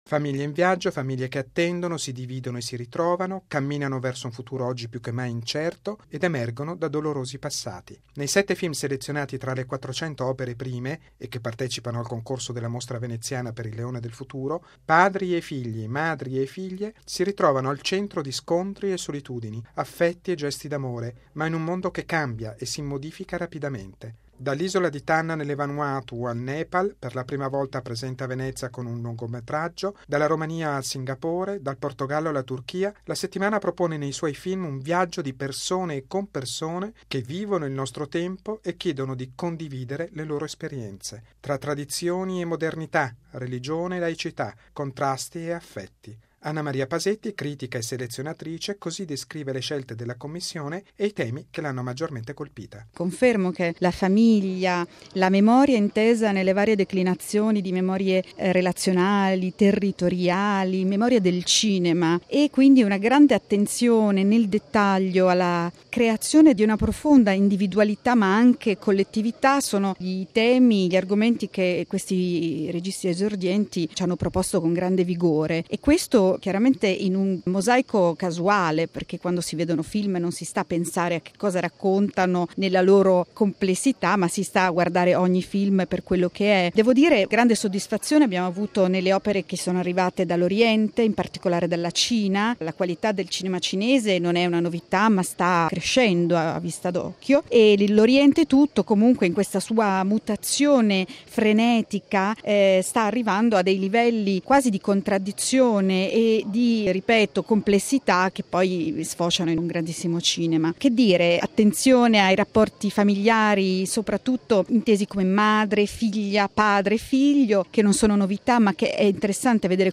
Sette film in concorso per l’opera prima e tre eventi speciali, con un tema diversamente declinato dai registi: la famiglia e la memoria. Il servizio